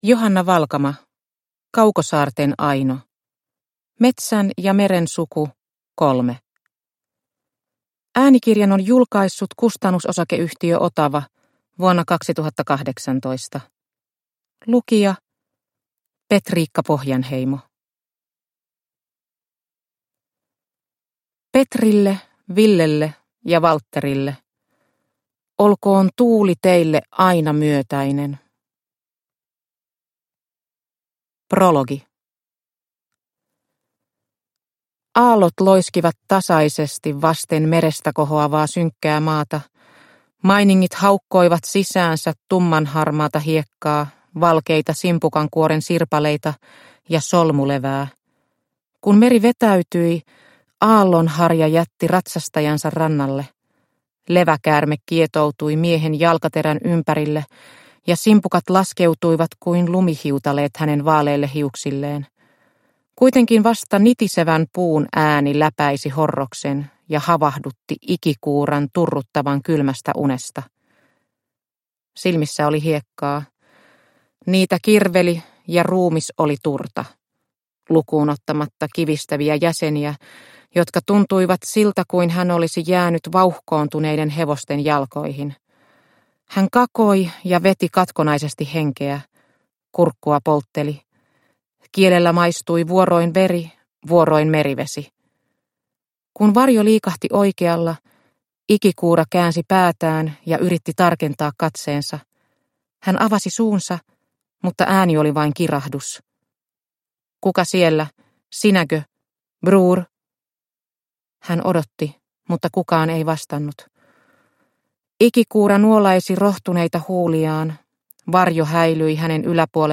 Kaukosaarten Aino – Ljudbok – Laddas ner